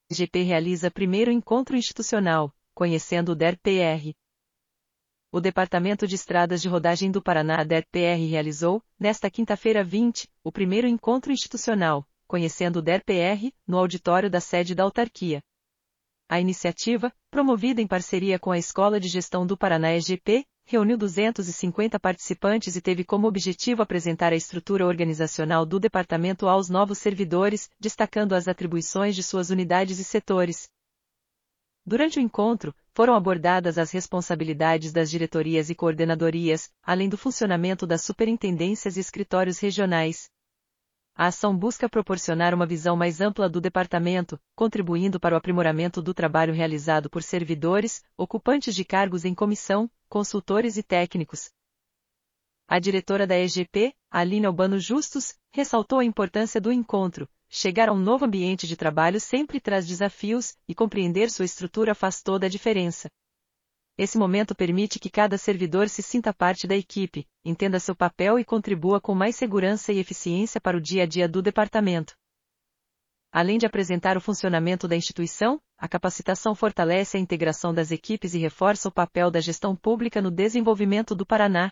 egp_realiza_i_encontro_institucional_audio_noticia.mp3